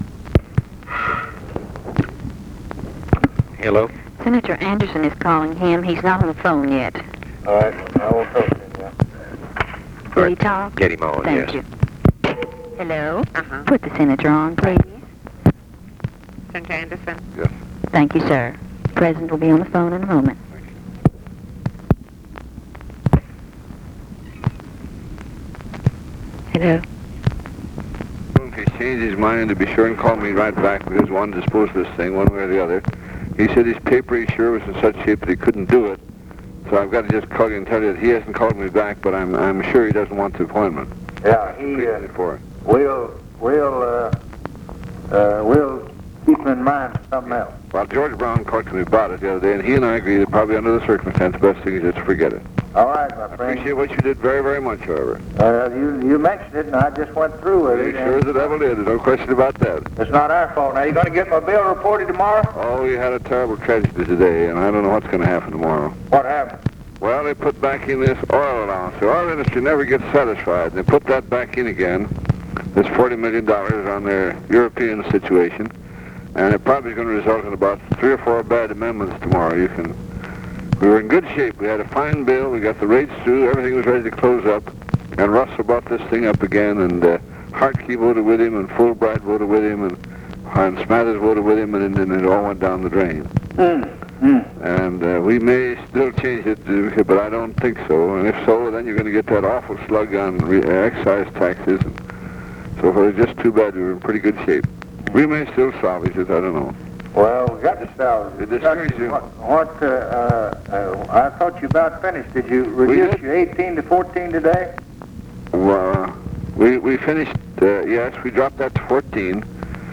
Conversation with CLINTON ANDERSON, January 22, 1964
Secret White House Tapes